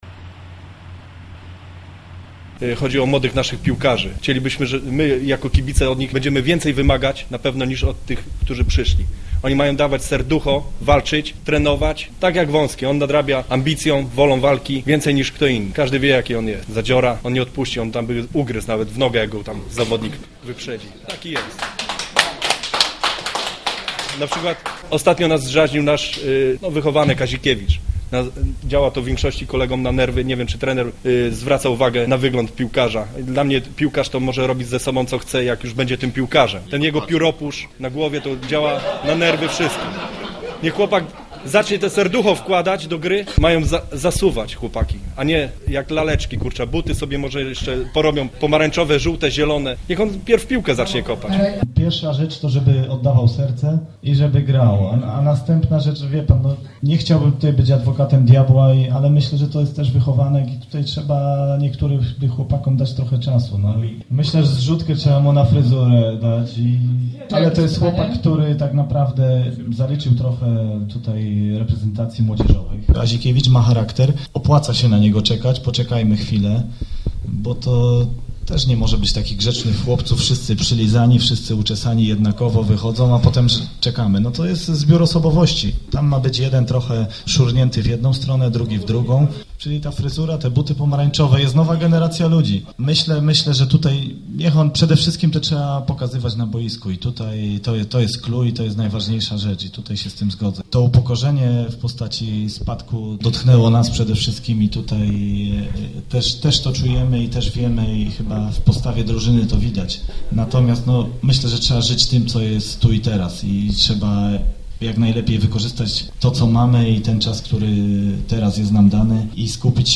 Najciekawszym fragmentem spotkania były jednak pytania od kibiców.